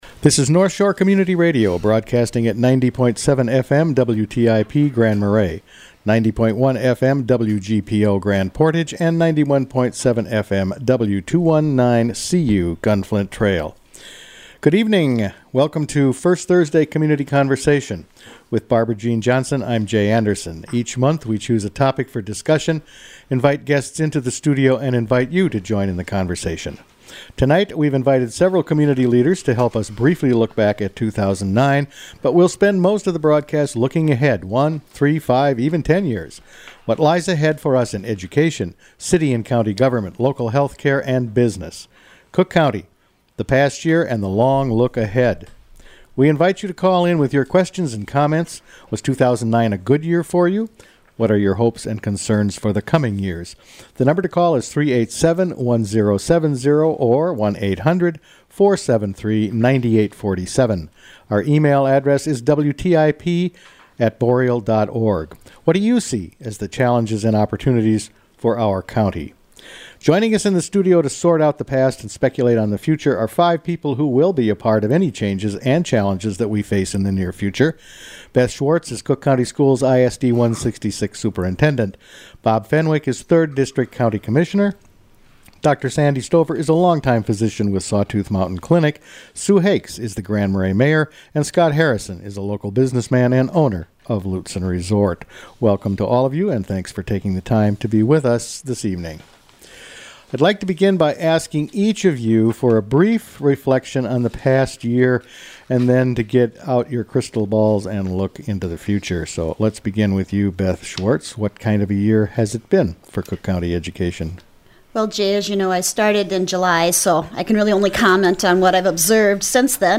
community members that called in to participate in a discussion about the future of our community. Program: Community Conversations